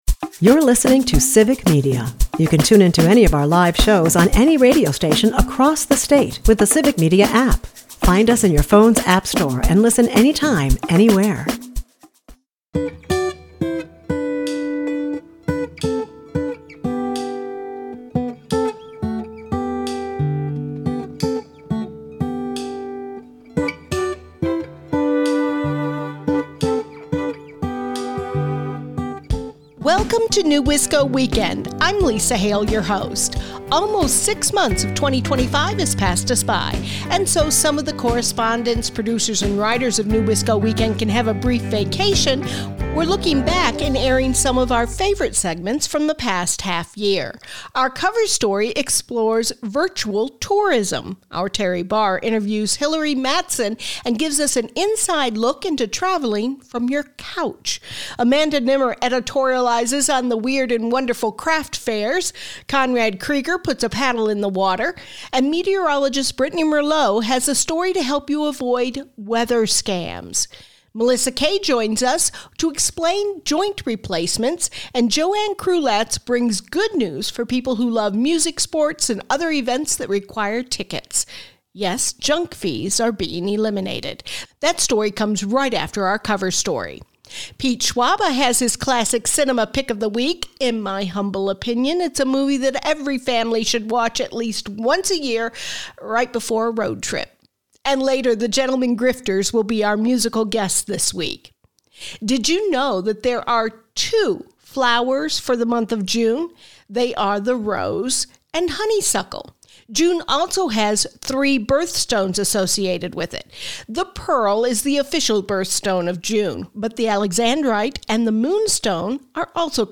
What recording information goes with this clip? NEWisco Weekend is a part of the Civic Media radio network and airs Saturdays at 8 am and Sundays at 11 am on 97.9 WGBW and 98.3 and 96.5 WISS.